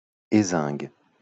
Hésingue (French pronunciation: [ezɛ̃ɡ]